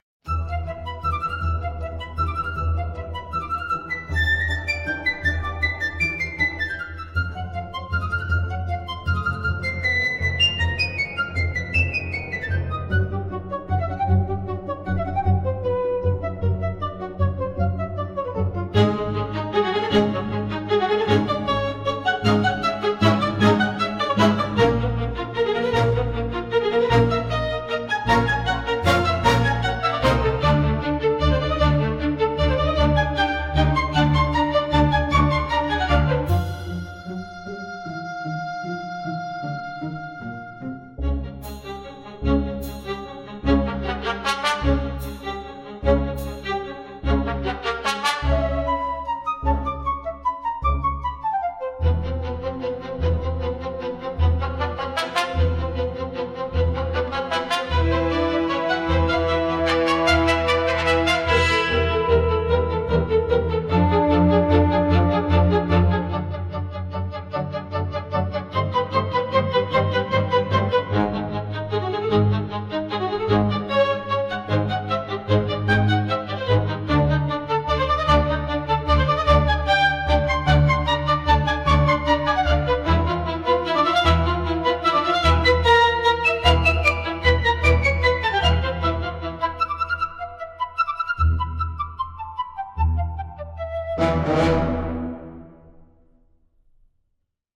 Great for movements and interpretive modern ballet.